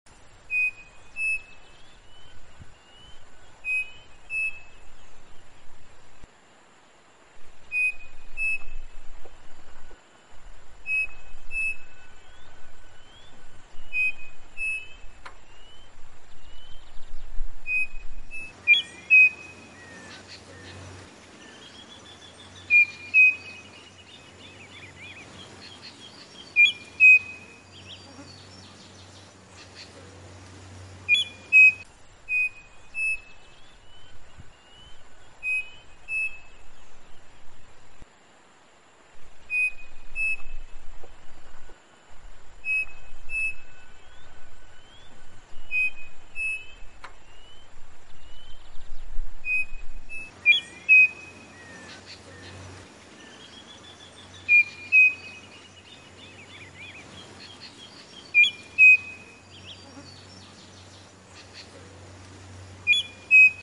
Saci cantando . Siga: sound effects free download